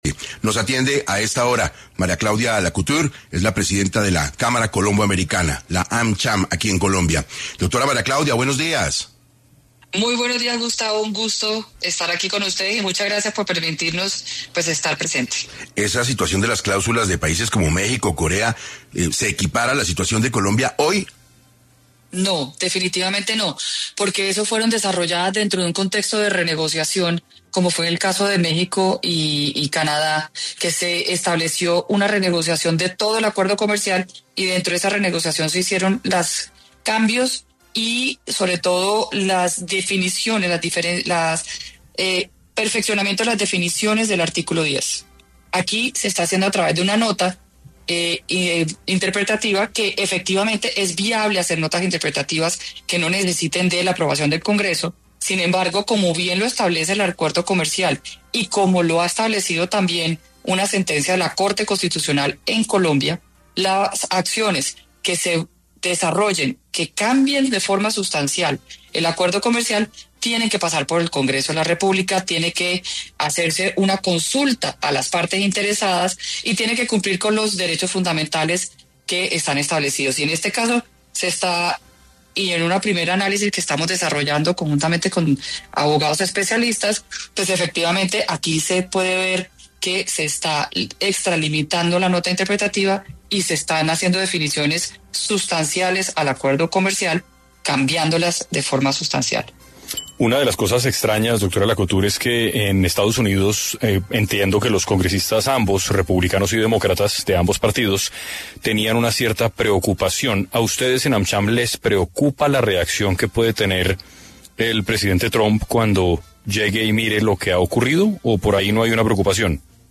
En 6AM estuvo María Claudia Lacouture, presidenta de la Cámara Colombo Americana, AmCham Colombia, donde se refirió a los cambios del TLC entre Estados Unidos y Colombia.